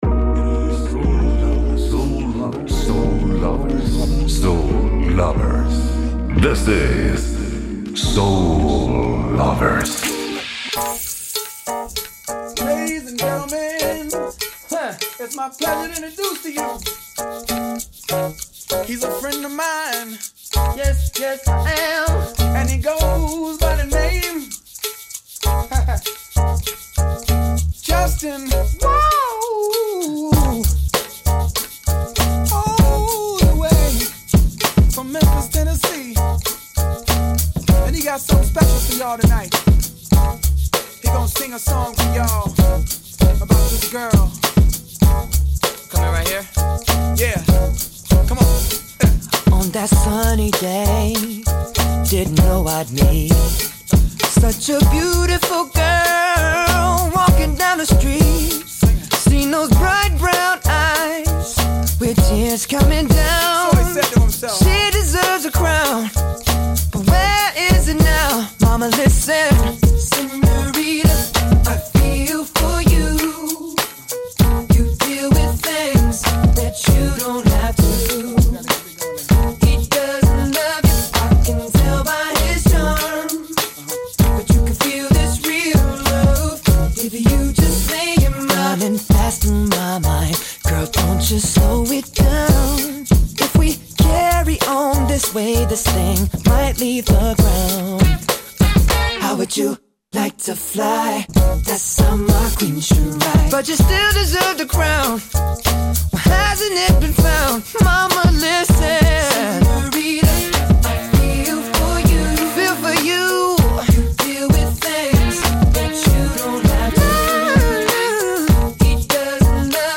La musica in uscita in questi primi giorni del mese marziano sembra avere proprio questa caratteristica: un po’ calda, con qualche graffio e qualche morso ma, sostanzialmente, ottimista.